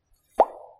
古风转场1
描述：水滴转场
标签： 转场 水滴转场 古风转场
声道立体声